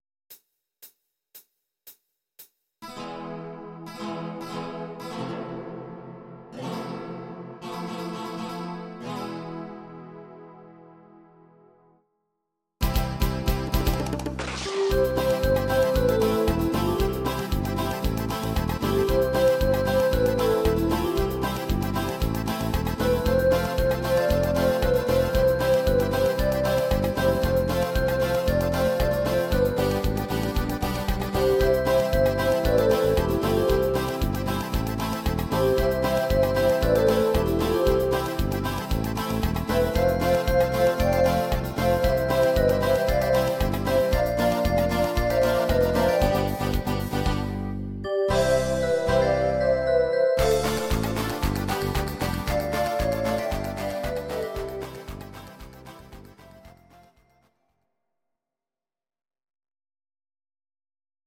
Audio Recordings based on Midi-files
German, 1990s